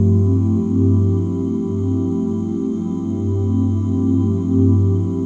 OOH G MIN9.wav